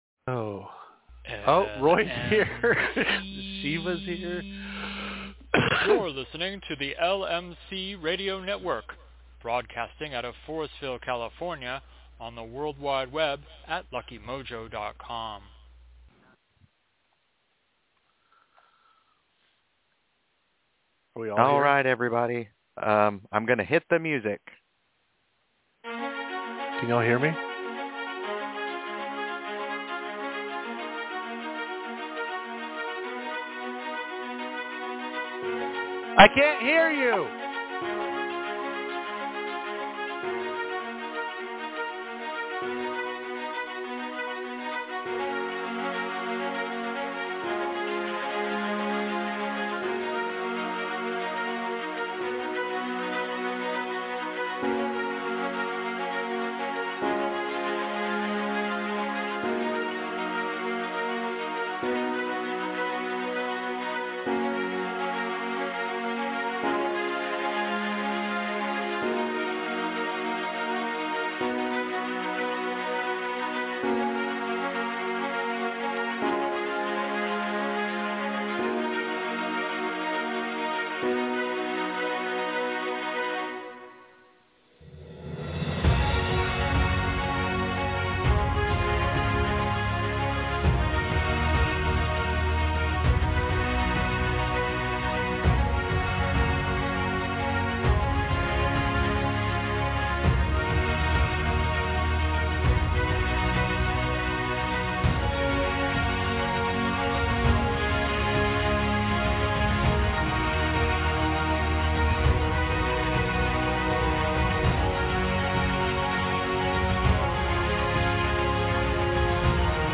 We begin this show with an interview with our guest followed by a discussion on how to develop and trust your intuition to improve your readings and magical practices.